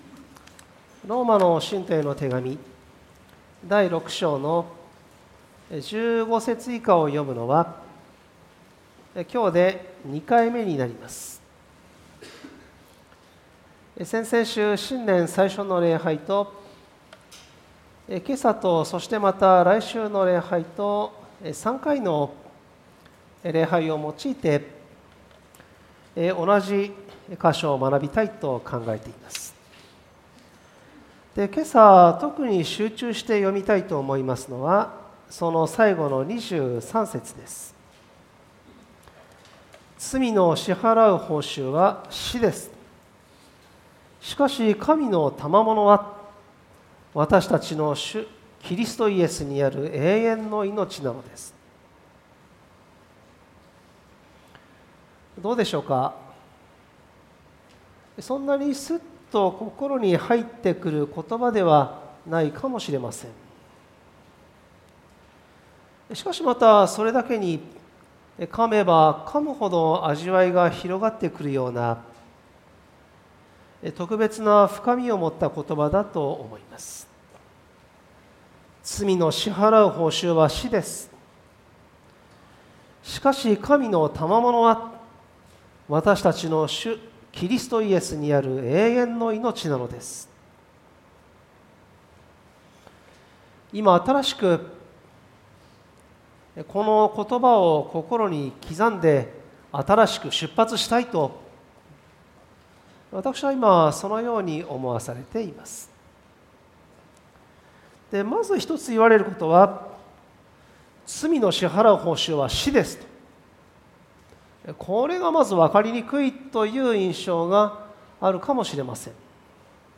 主日礼拝